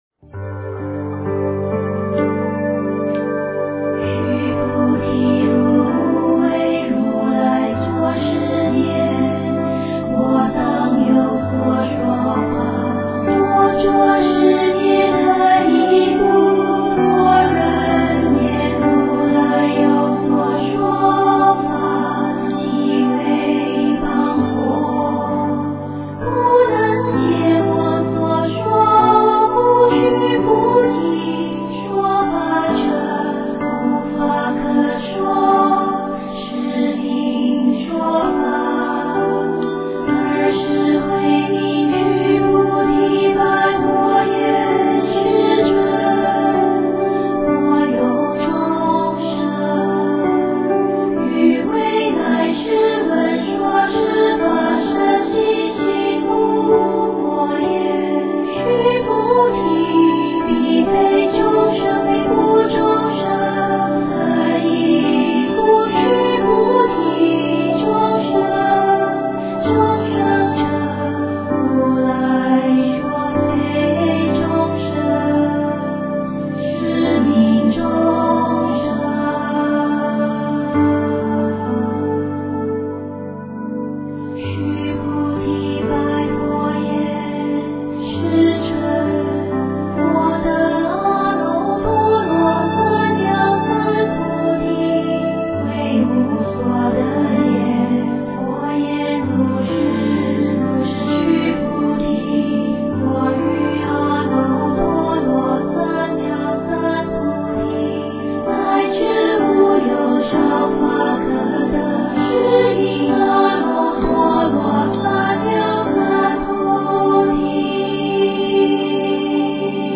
佛音 诵经 佛教音乐